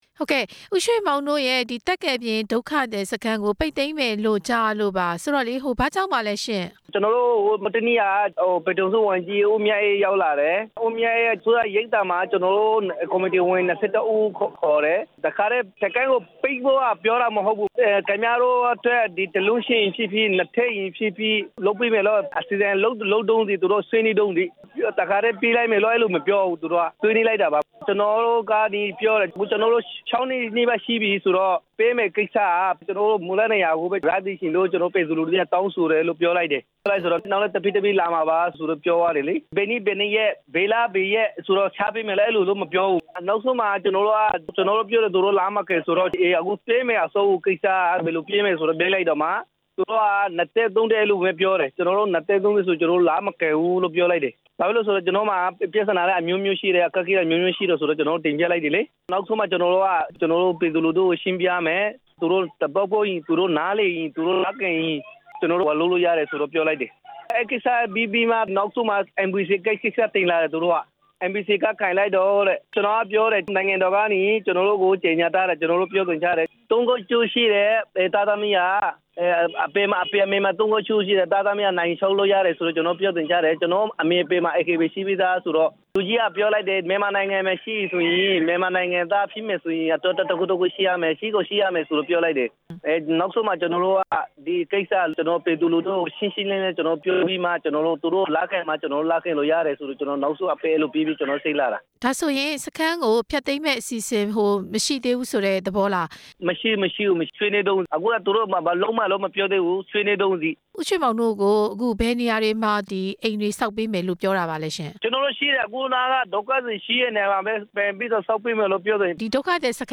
စစ်တွေက မွတ်စလင်ဒုက္ခသည်စခန်း ပိတ်သိမ်းရေး အစီအစဉ် ဆက်သွယ်မေးမြန်းချက်